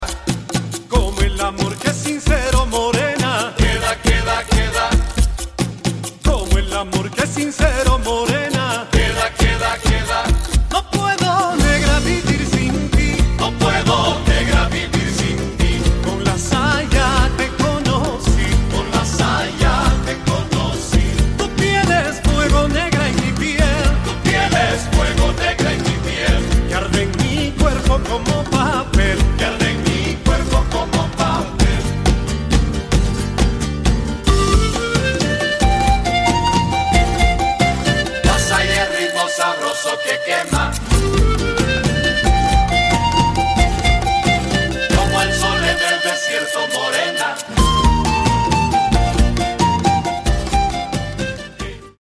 Hermoso folklore boliviano!